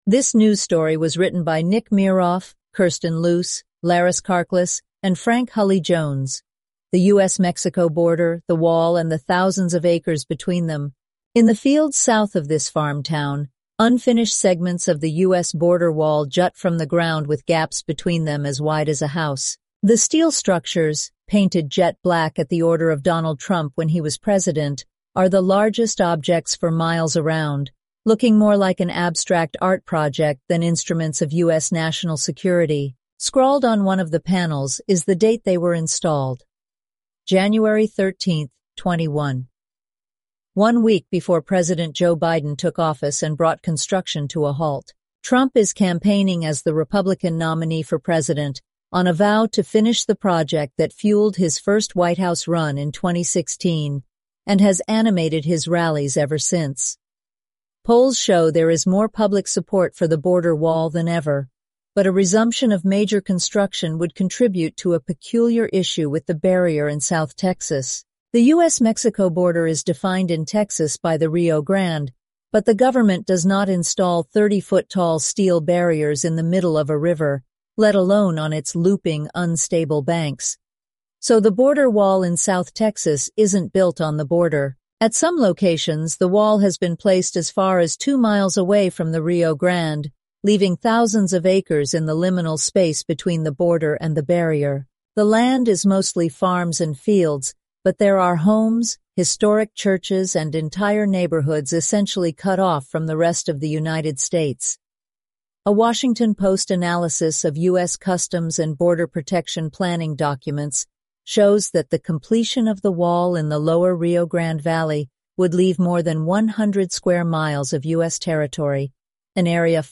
eleven-labs_en-US_Maya_standard_audio.mp3